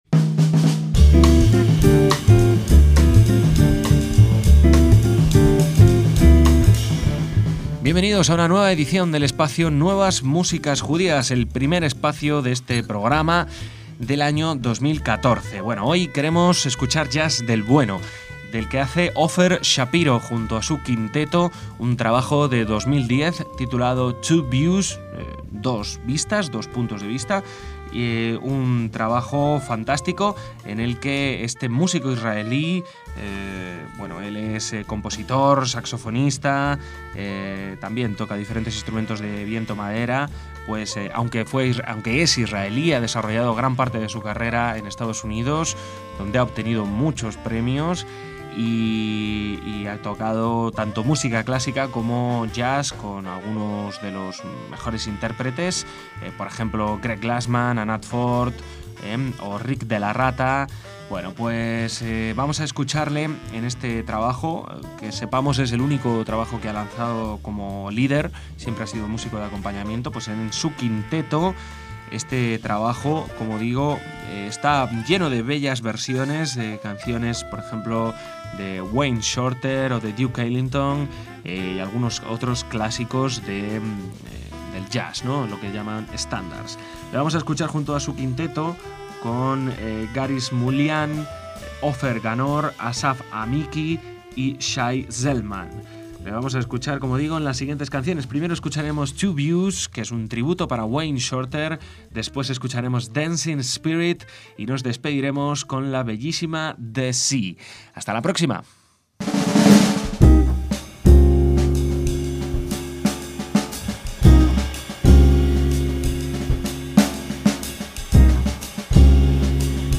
jazz
saxo barítono
guitarra eléctrica
contrabajo
batería